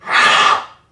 NPC_Creatures_Vocalisations_Robothead [7].wav